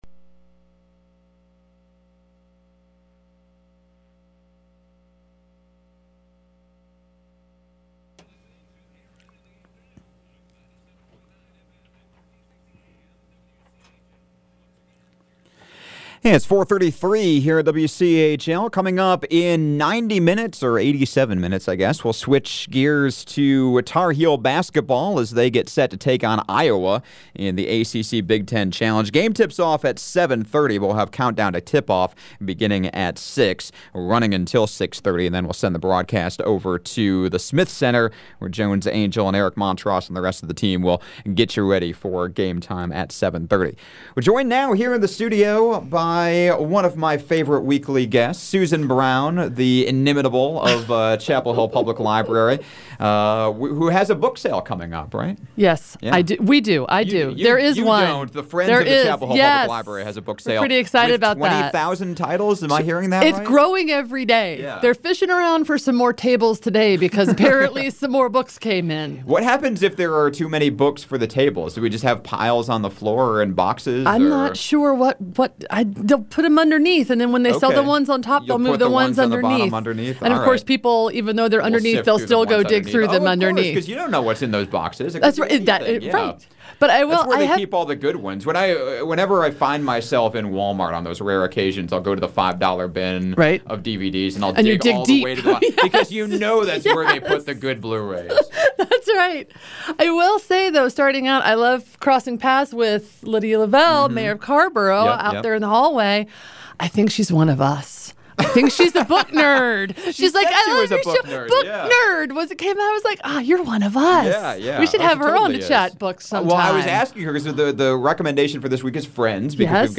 The Friends of the Library are having a book sale – so what better topic than friends? (Audio begins after a 10-second delay.)